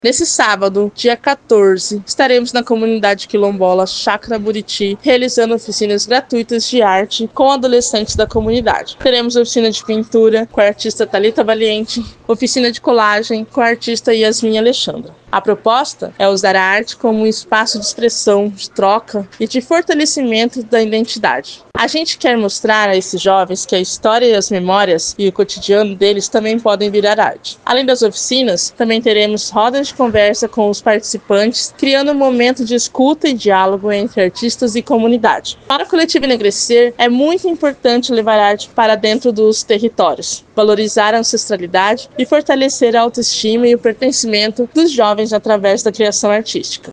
Em entrevista ao programa Agora 104